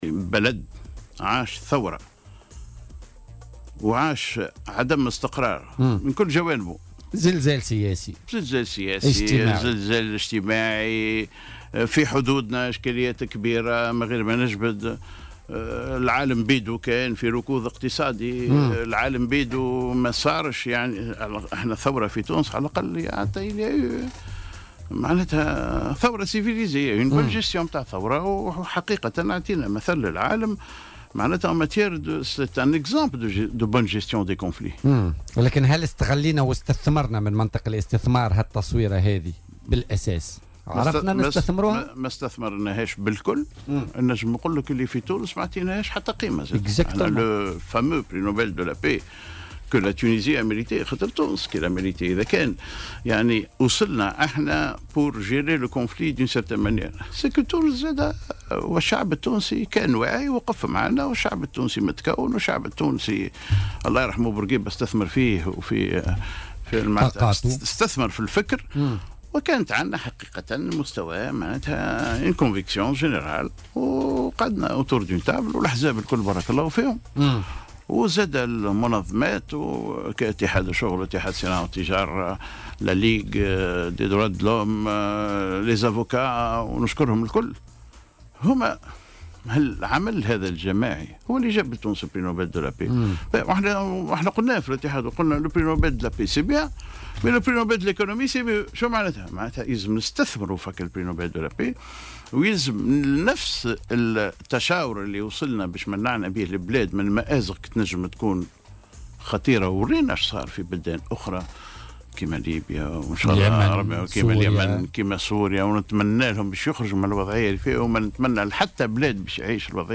وأوضح ماجول ضيف برنامج "بوليتيكا" اليوم أن تونس لا تزال تعاني من أزمة اقتصادية واجتماعية وسياسية خانقة، مشيرا إلى أنها فوتت على نفسها فرصة إيجاد حلول في ظل غياب سياسة واضحة لدفع النمو وتوفير مناخ اقتصادي من شأنه أن يحث على الاسثمار.